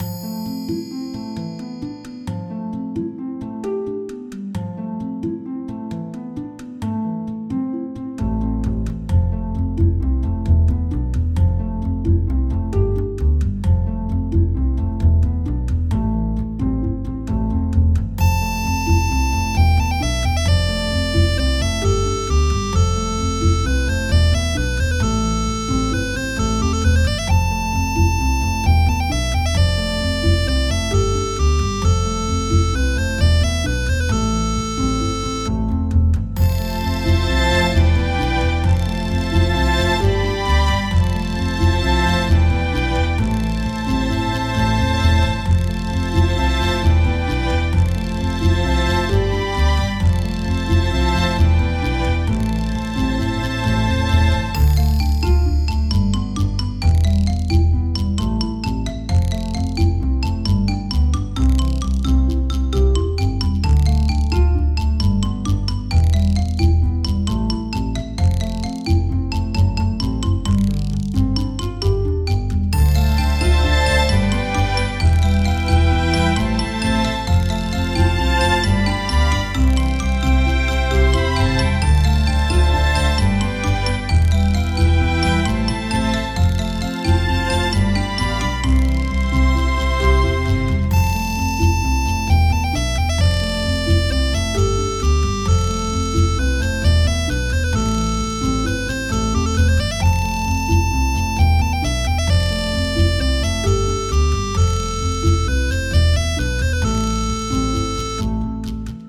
フィールド